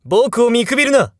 日本語 English 前 【ボイス素材】厨二病・邪気眼タイプ